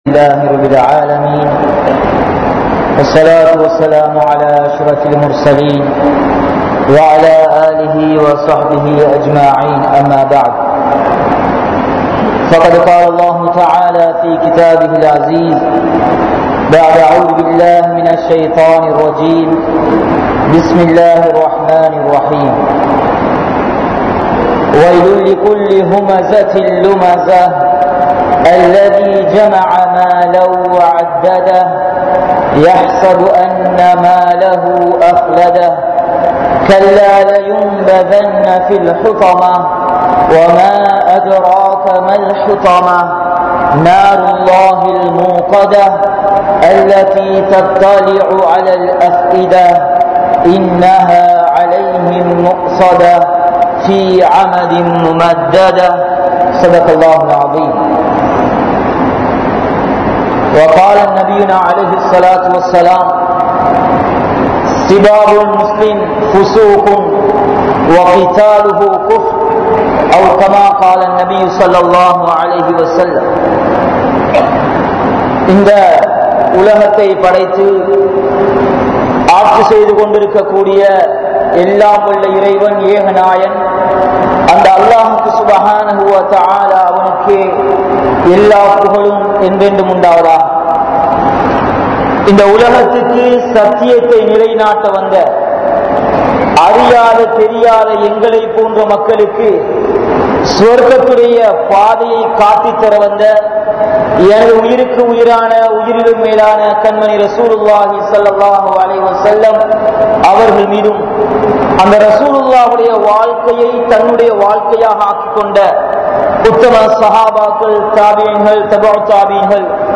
Naavin Vifareethangal (நாவின் விபரீதங்கள்) | Audio Bayans | All Ceylon Muslim Youth Community | Addalaichenai
Colombo 02, Wekanda Jumuah Masjidh